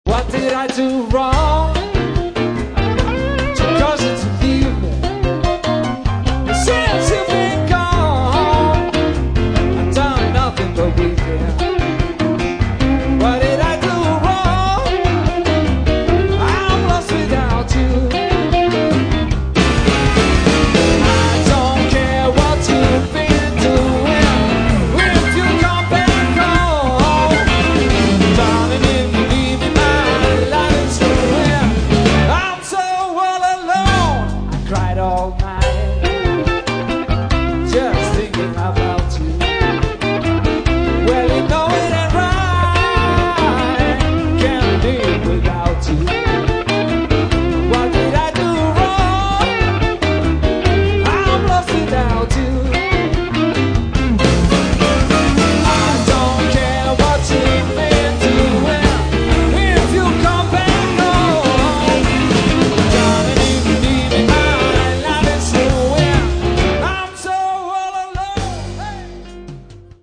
sem tekinn var upp á tónleikum á Grandrokk í mars 2003.